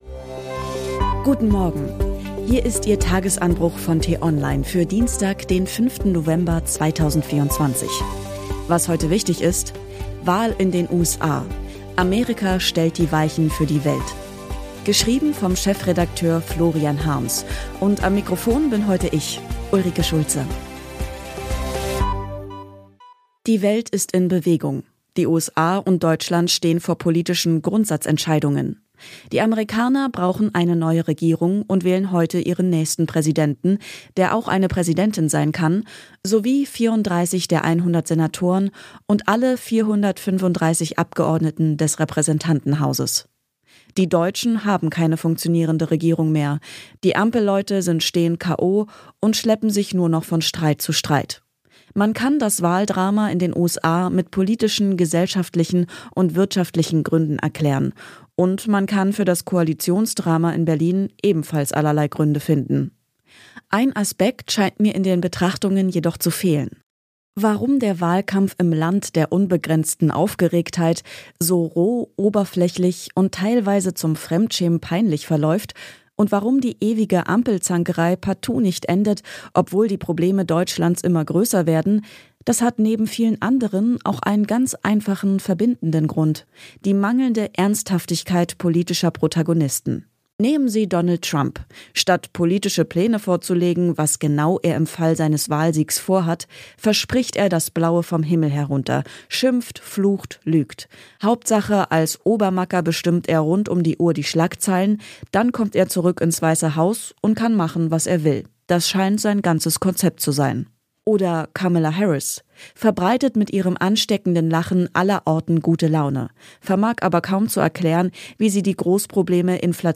Am Wochenende geht es in einer längeren Diskussion mit prominenten Gästen um ein aktuelles, politisches Thema.